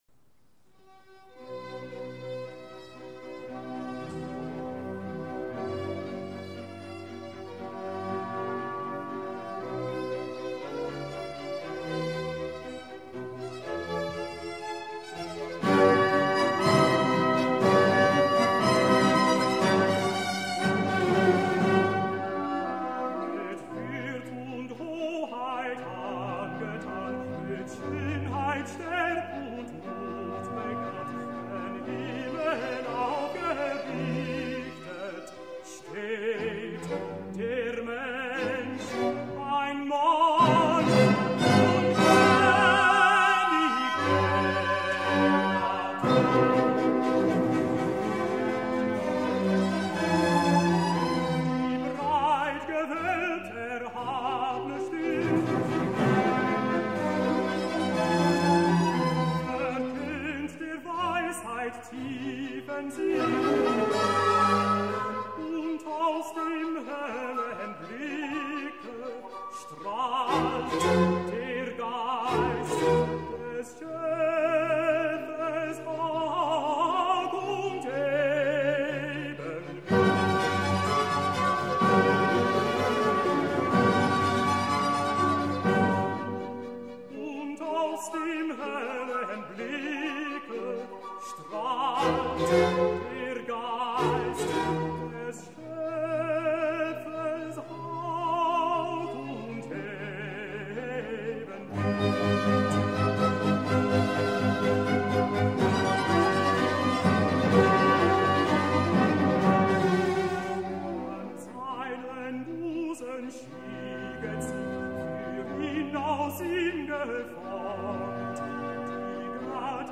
Neil Mackie singsDie Schöpfung: